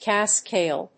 カスク・エール